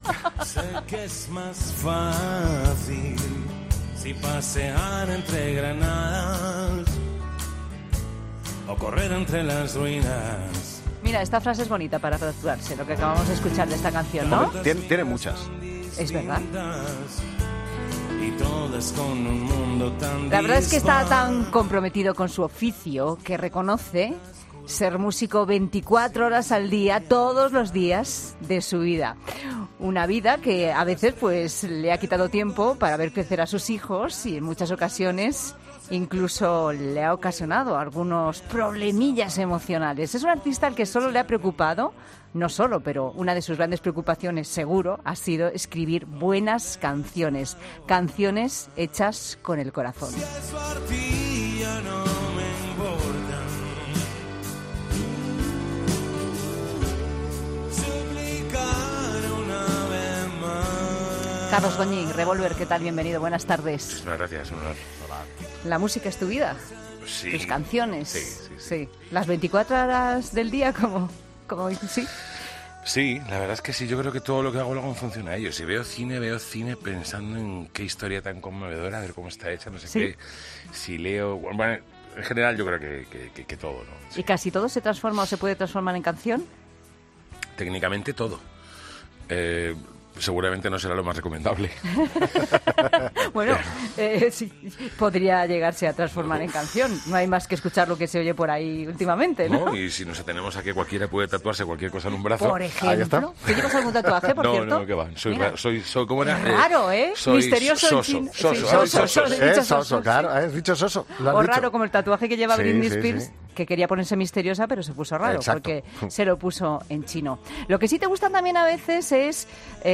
Carlos Goñi habla de su nuevo disco en COPE después de sorprender al público de su último directo, que no esperaba el nuevo trabajo del grupo